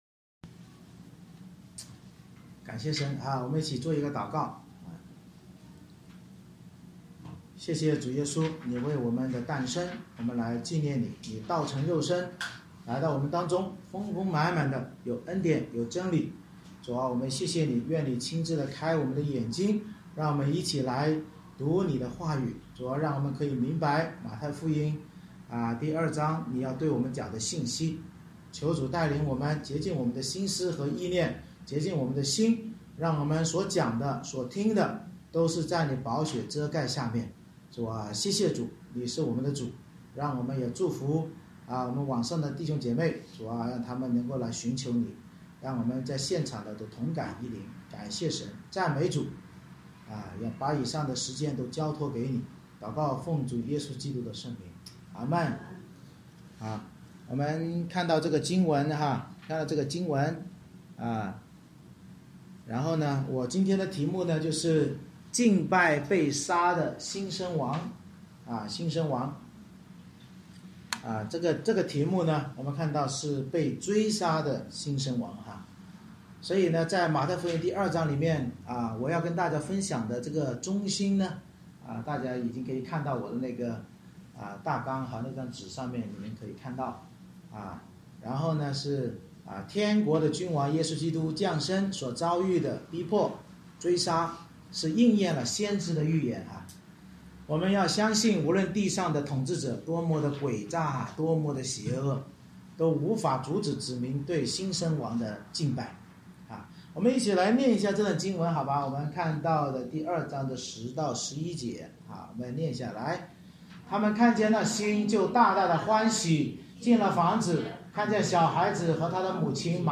马太福音2章 Service Type: 主日崇拜 天国君王耶稣基督的降生所遭遇的逼迫追杀应验了先知预言，我们要相信无论地上统治者多么诡诈邪恶，都无法阻止子民对新生王的敬拜。